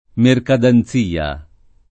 mercatanzia [merkatanZ&a] (region. antiq. mercadanzia [